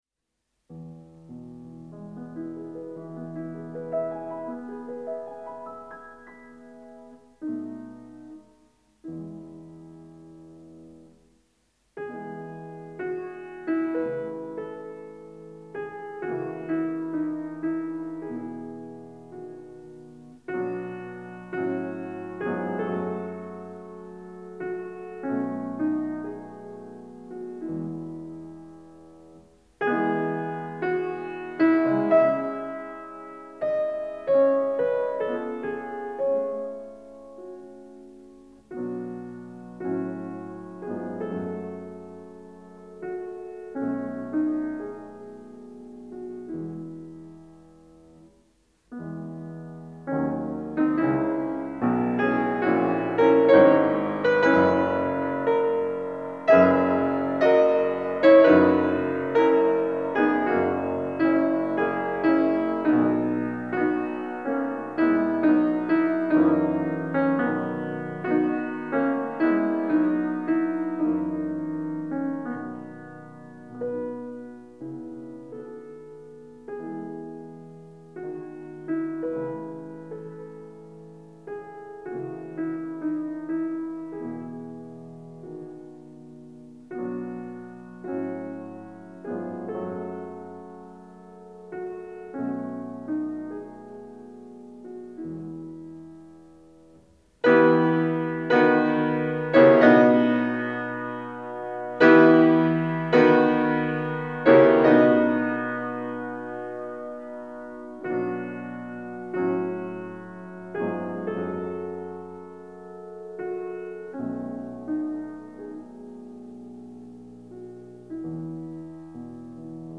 Образцами  нового жанра романтической фортепианной музыки композитора стали лирические миниатюрные пьесы - «Песни без слов».
В противовес распространившемуся в то время эффектному бравурному пианизму Мендельсон создал пьесы в камерном стиле, выявляя, прежде всего кантиленные, певучие возможности инструмента.
Аудио: Ф. Мендельсон тетрадь 2, соч. 30 No.3 ми мажор